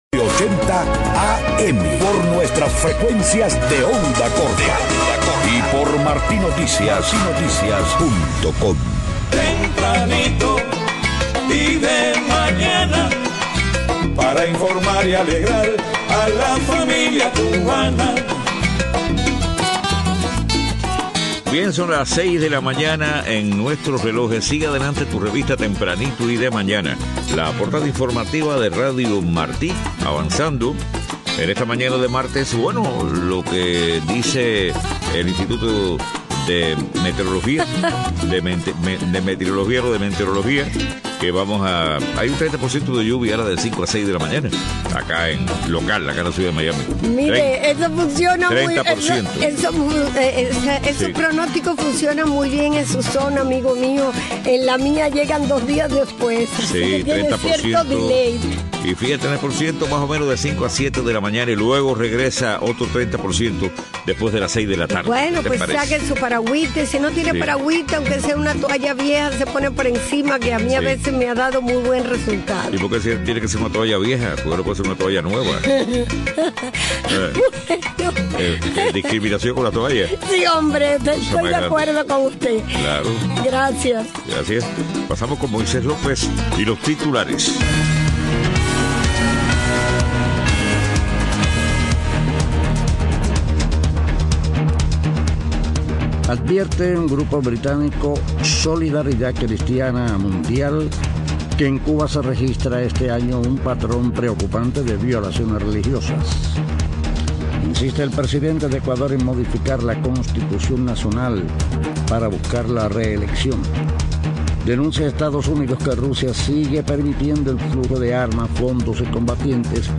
6:00 a.m. Noticias: Grupo británico Solidaridad Cristiana advierte que en Cuba hay un patrón preocupante de violaciones religiosas. Presidente de Ecuador insiste en modificar Constitución Nacional para buscar la reelección.